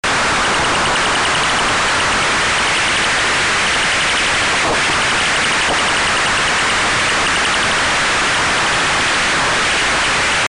I used an AR100 ultrasonic receiver to generate a wideband recording of a group of Western Pipistrelle bats. I then reprocessed the recording digitally using each of the bat detection techniques to generated MP3 files covering the same 10 second segment of the original recording.
Technique #1 : Frequency Division
Its performance is limited because 1) it does not preserve amplitude information, 2) it generates a high level of artificial harmonic content that cannot be removed, and 3) it does not distinguish well between the actual signal and the background noise, so it is very noisy.
Click on the microphone icon for a frequency division demo,  but BE WARNED,  the noise content of this technique is very high so keep your volume down!